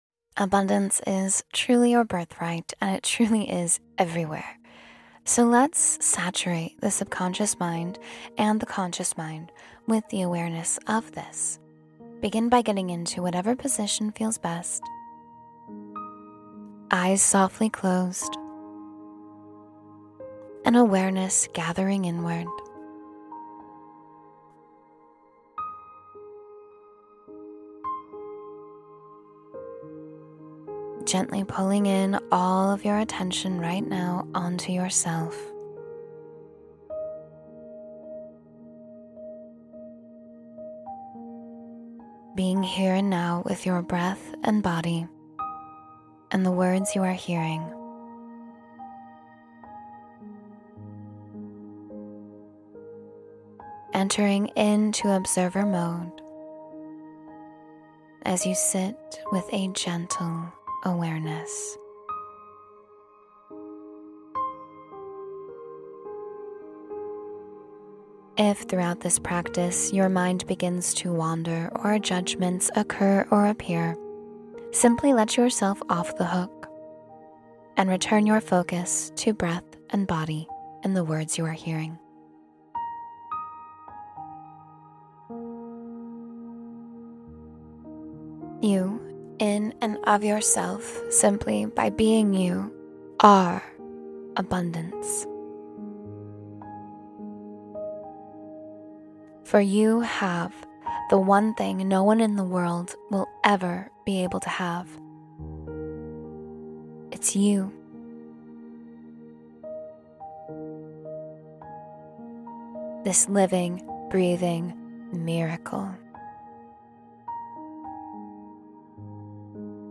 Attract Abundance with Guided Intention — Meditation for Wealth and Prosperity